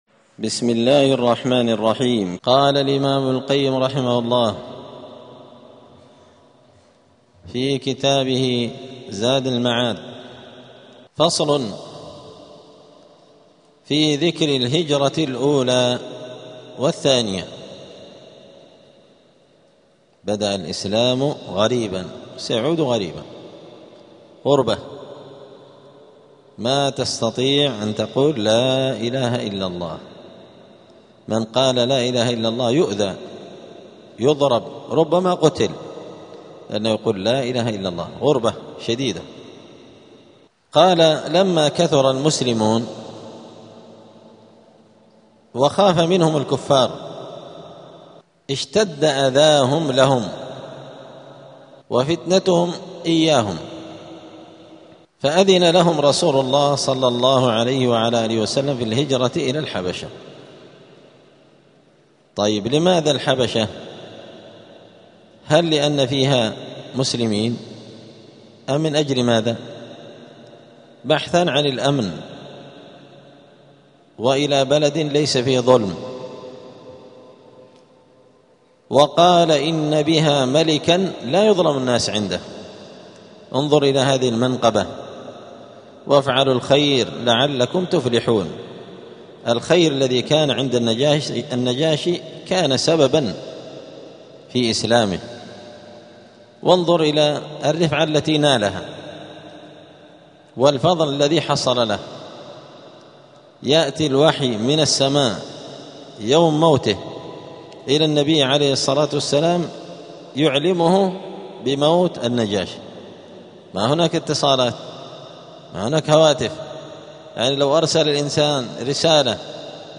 *الدرس السابع عشر (17) {ﻓﺼﻞ ﻓﻲ ذكر الهجرة الأولى والثانية}.*